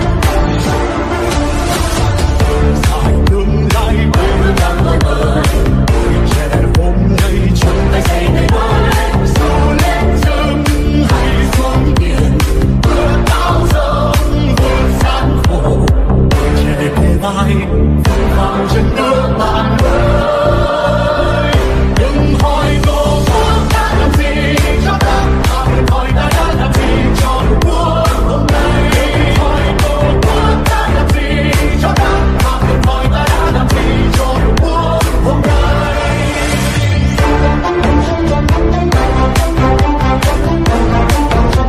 Nhạc Cách Mạng.